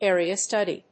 アクセントárea stùdy